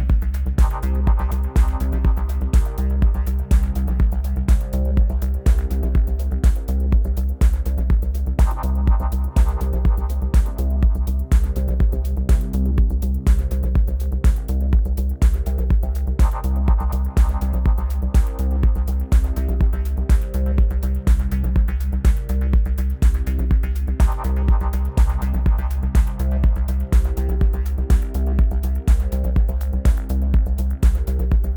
Черновик для меня ок.